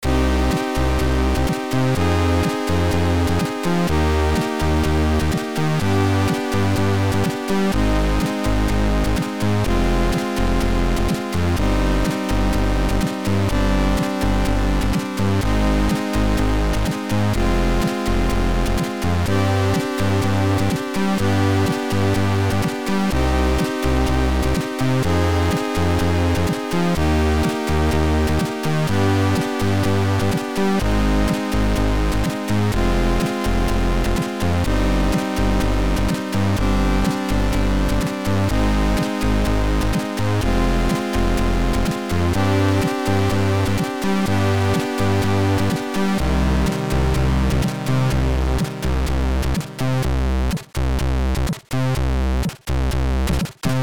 AHX v2 Tracker